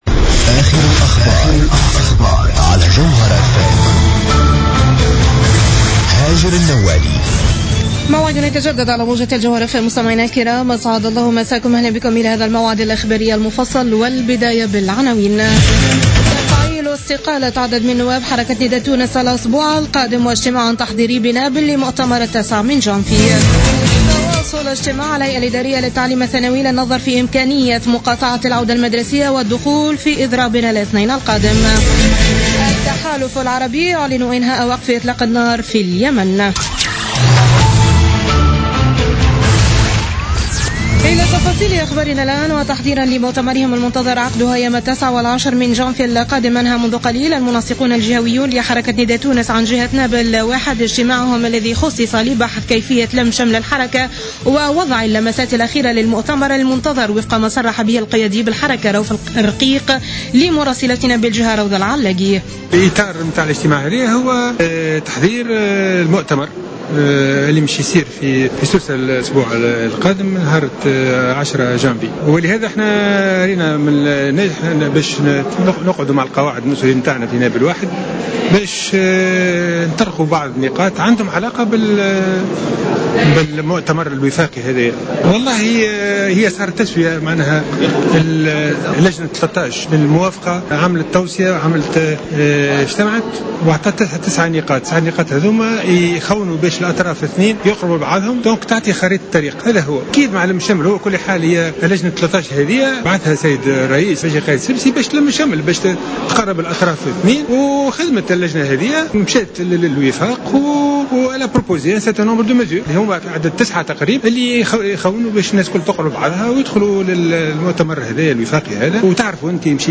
نشرة أخبار السابعة مساء ليوم السبت 2 جانفي 2016